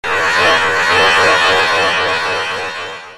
radar_blowout.ogg